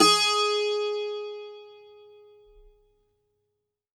STRINGED INSTRUMENTS
52-str09-bouz-g#3.wav